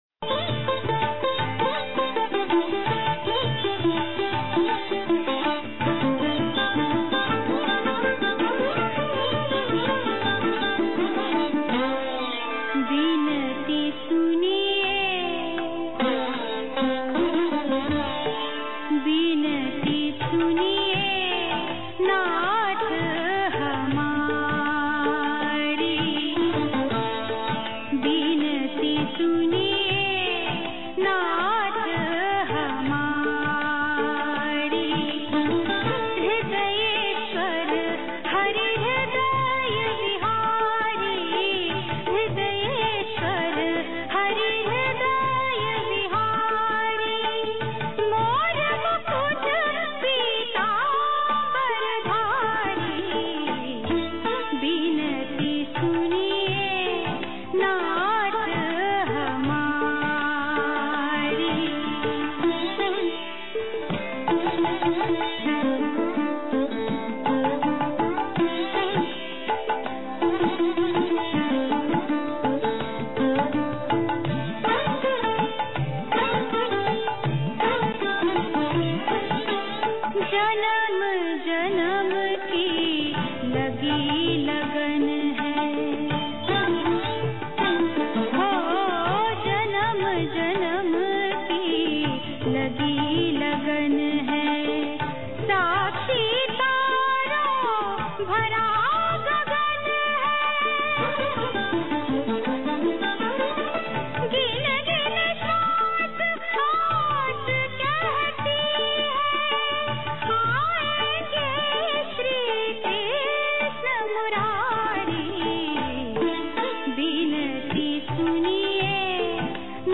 Yada Yada Hi Dharmasya [Minor noise in audio] यदा यदा हि धर्मस्य
(Rajrishi Hall, GS @ 8:15 am) Speaker